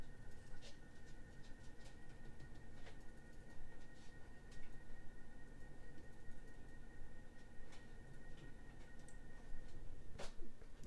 PC (Wakü?) piept dauerhaft